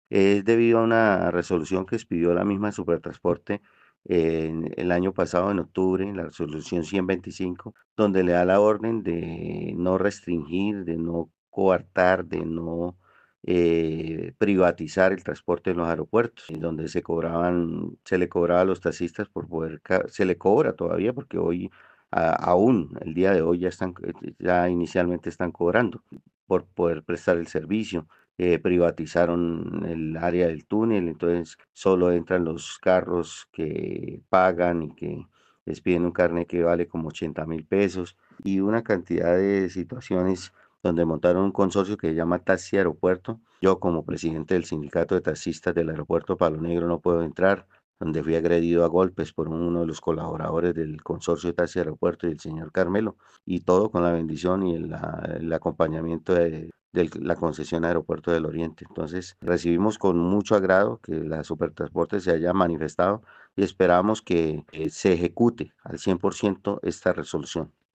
sindicato de taxistas aeropuerto